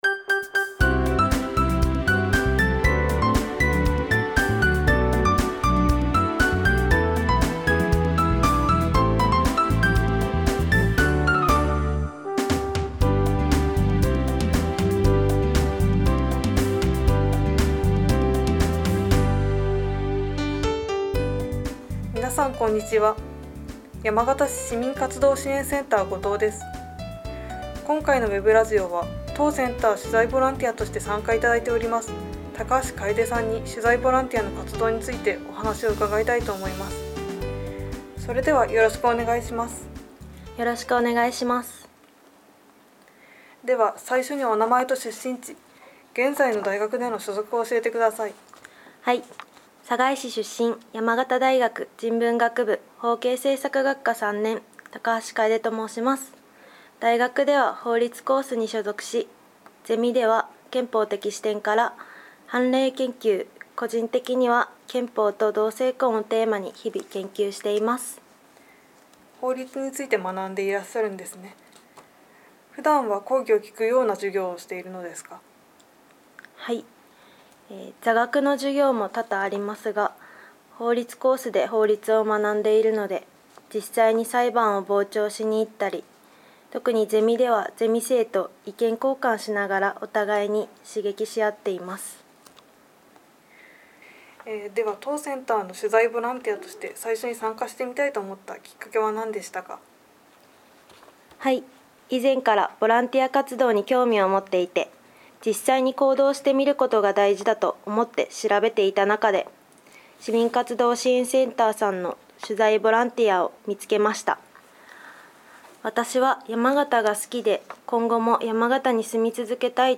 ウェブラジオ2019年1月